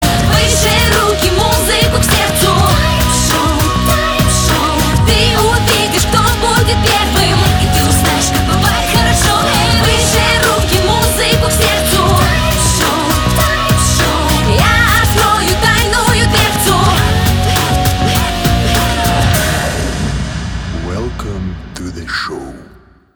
• Качество: 256, Stereo
поп
женский вокал
мотивирующие
зажигательные
dance